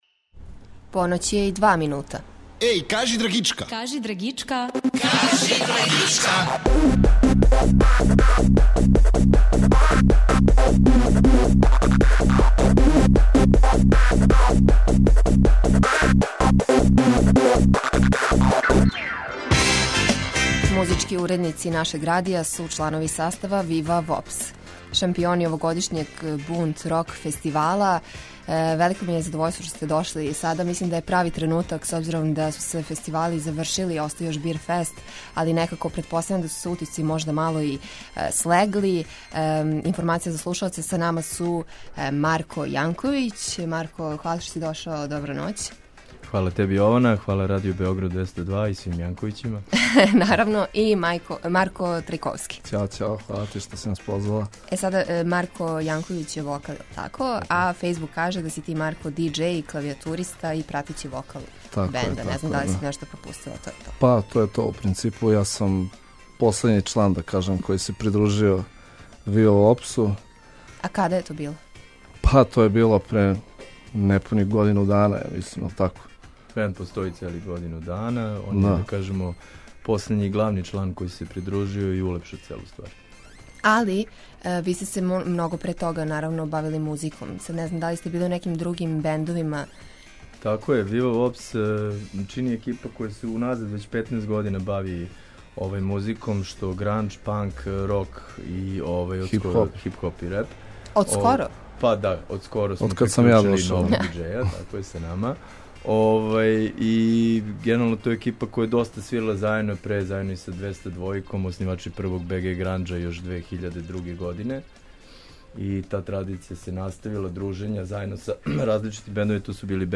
Музички уредници нашег радија су чланови састава Вива Вопс, шампиони овогодишњег Бунт рок фестивала.